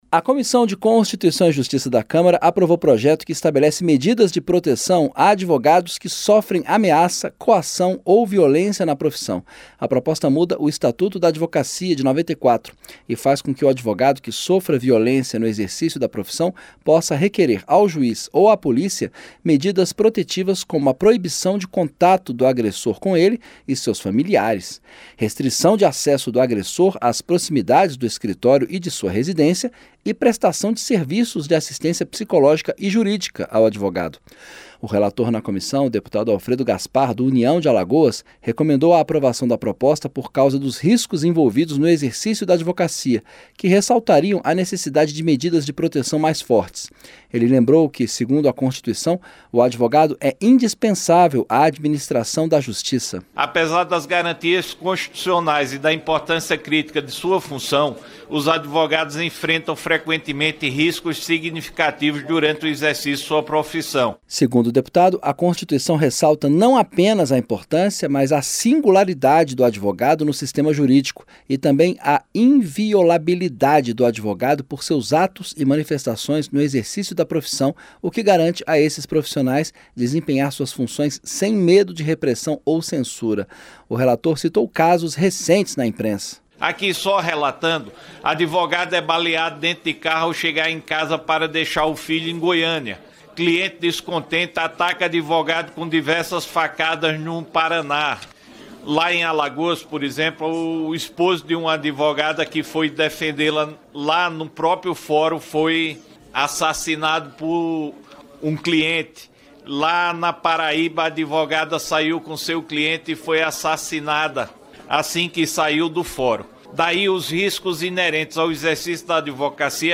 CÂMARA APROVA MEDIDAS DE PROTEÇÃO A ADVOGADOS QUE SOFREM AMEAÇAS. O REPÓRTER